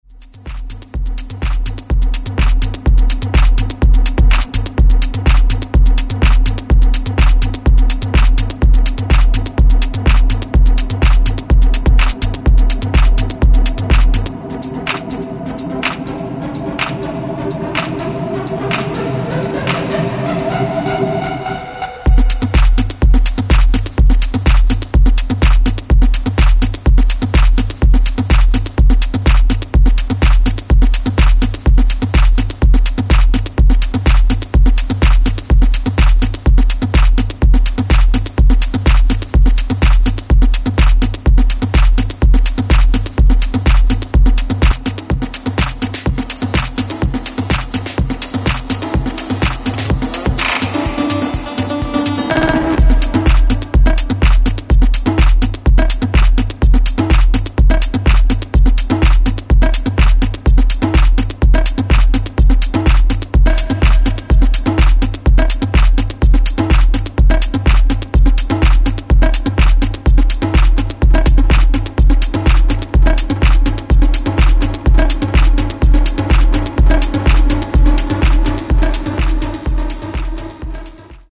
Styl: Techno, Minimal